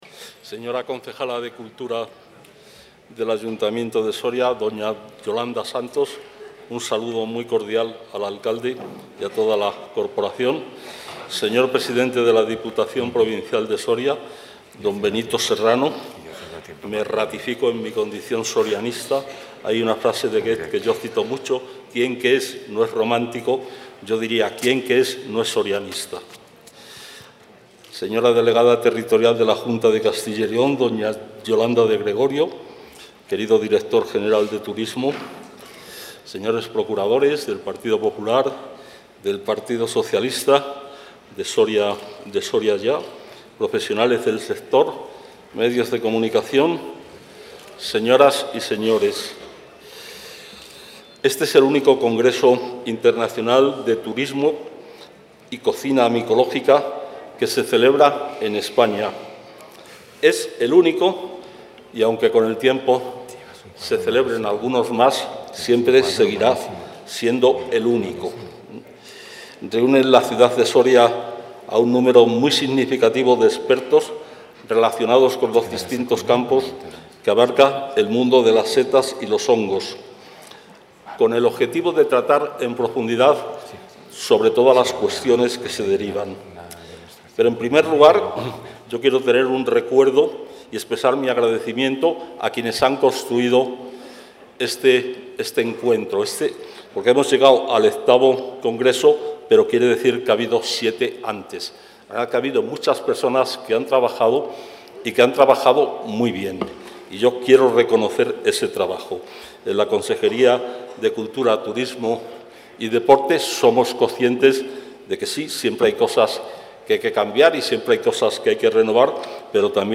El consejero de Cultura, Turismo y Deporte, Gonzalo Santonja, ha inaugurado hoy el VIII Congreso Internacional de Cocina y Turismo Micológico...
Intervención del consejero de Cultura, Turismo y Deporte.